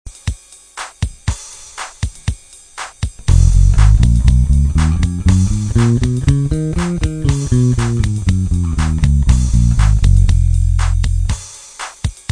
Cliquer sur Ex et vous  aurez un exemple sonore en F (Fa).
LOCRIEN